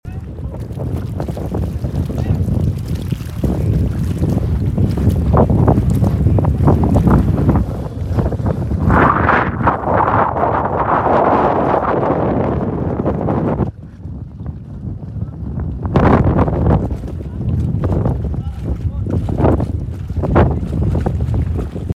A sense of nature in sound effects free download
A sense of nature in the Ras Mohammed Nature Reserve in Sharm El-Sheikh